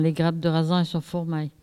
Mémoires et Patrimoines vivants - RADdO est une base de données d'archives iconographiques et sonores.
collecte de locutions vernaculaires